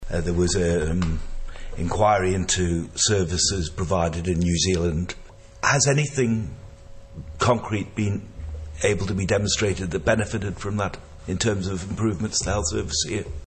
That's according to the Department of Health and Social Care - yesterday it was put under the spotlight during a Social Affairs Policy Review Committee hearing: